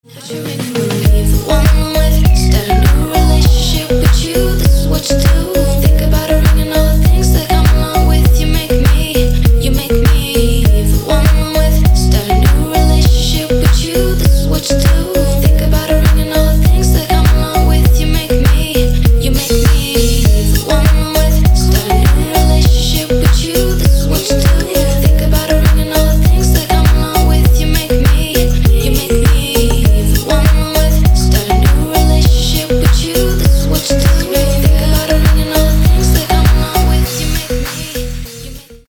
• Качество: 224, Stereo
женский вокал
мелодичные
dance
Electronic
спокойные
club
house
vocal